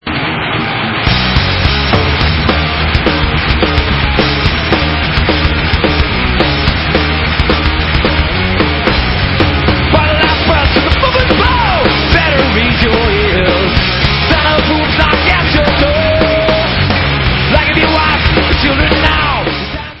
live
sledovat novinky v oddělení Heavy Metal